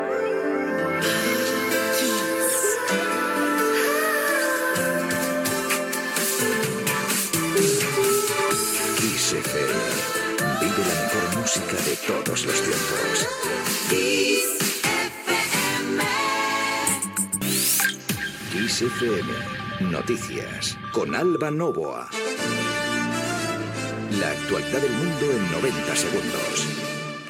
Indicatiu de la ràdio i notícies